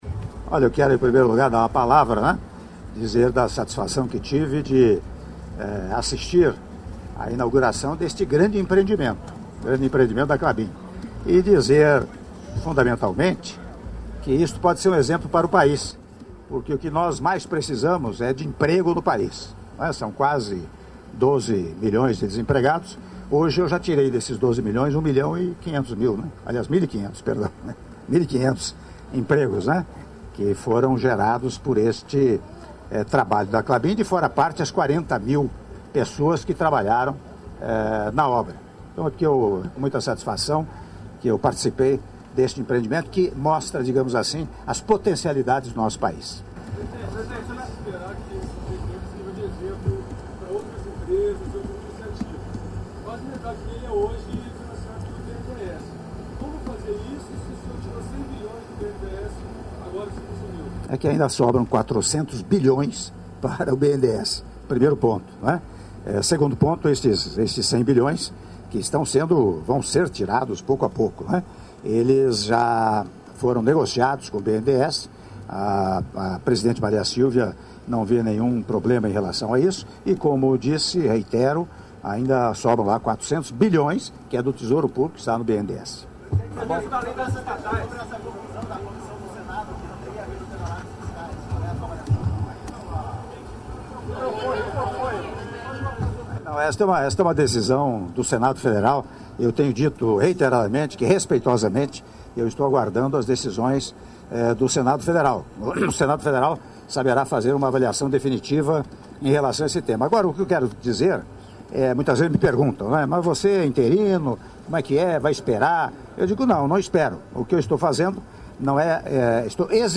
Áudio da entrevista do presidente da República em exercício, Michel Temer, após cerimônia de inauguração da nova fábrica de celulose da Klabin - Ortigueira/PR (03min13s)